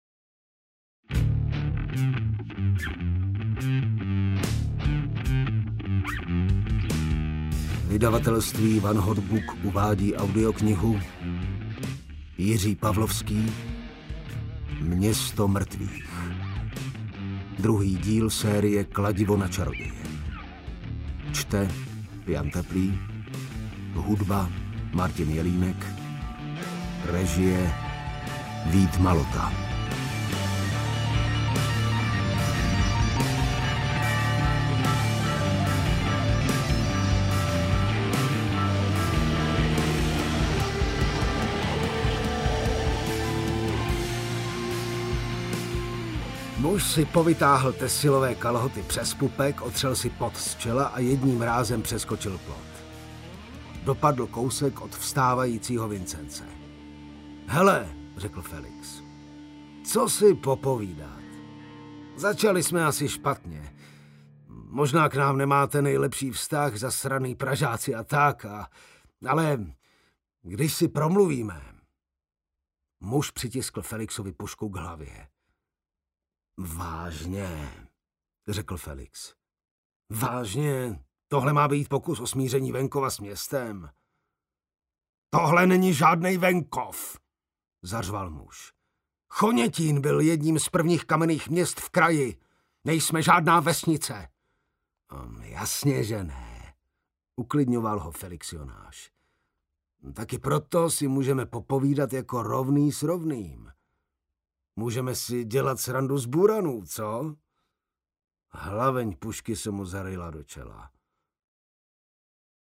Kladivo na čaroděje 2: Město mrtvých audiokniha
Ukázka z knihy